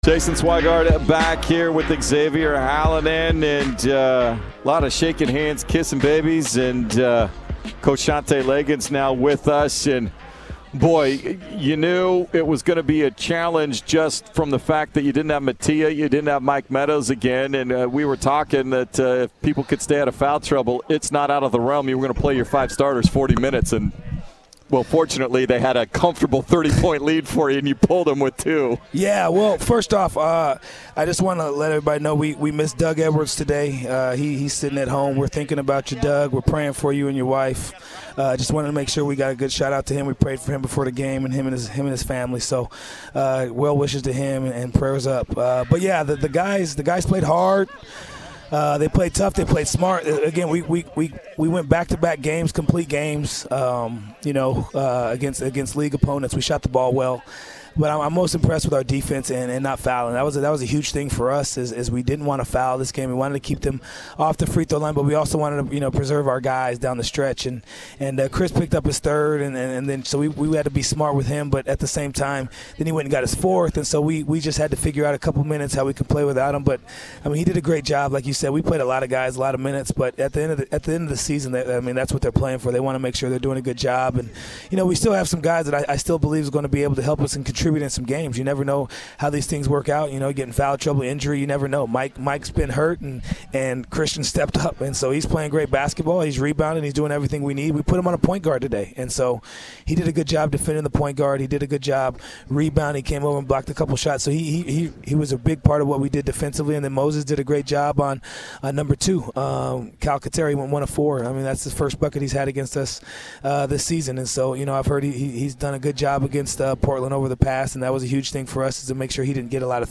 Men's Basketball Radio Interviews